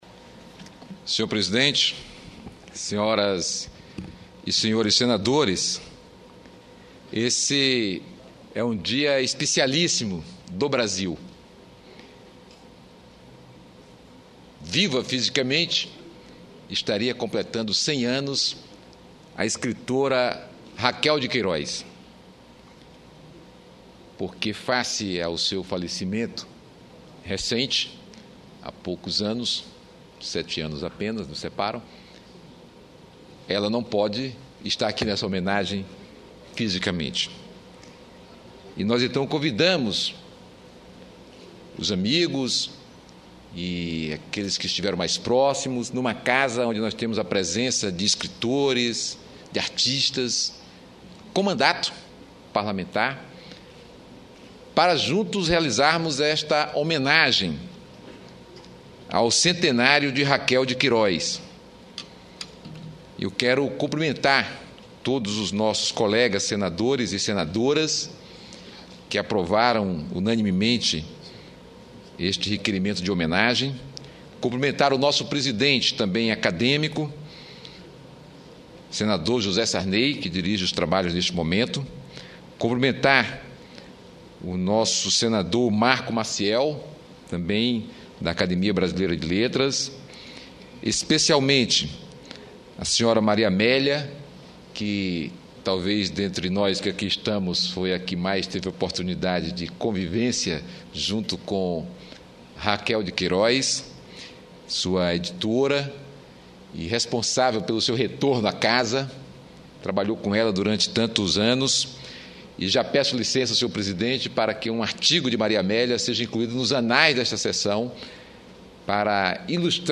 Discurso do senador Inácio Arruda